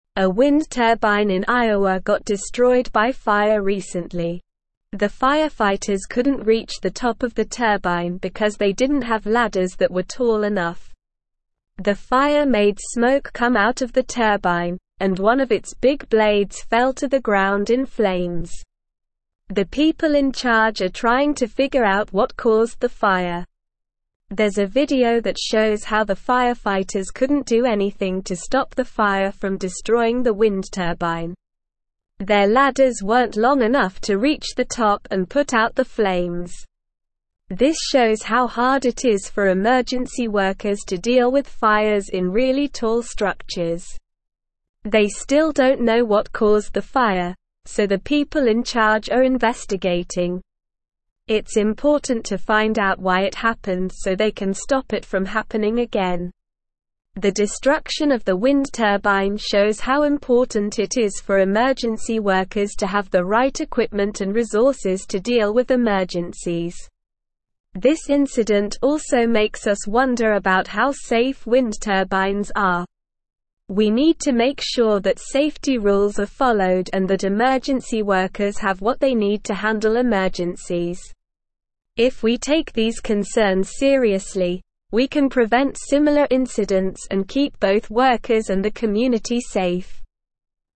Slow
English-Newsroom-Upper-Intermediate-SLOW-Reading-Fire-Destroys-Wind-Turbine-Due-to-Lack-of-Equipment.mp3